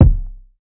Give in Kick.wav